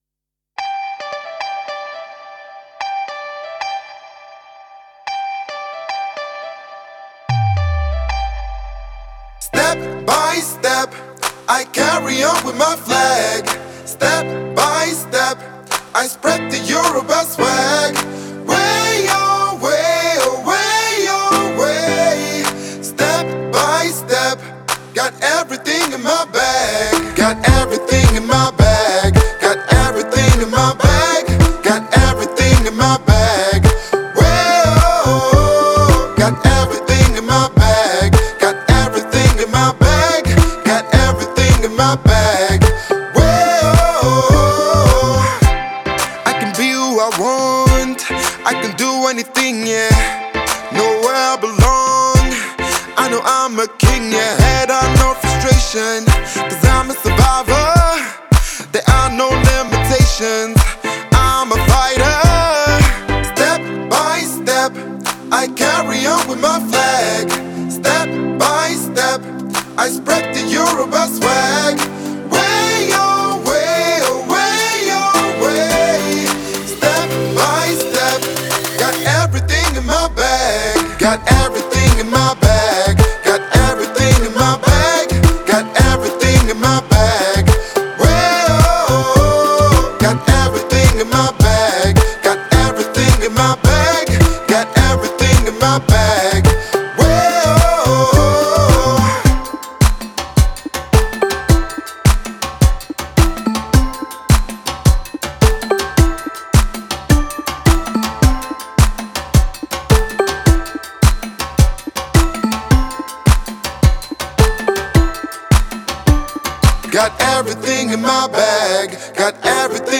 это энергичная и мелодичная песня в жанре поп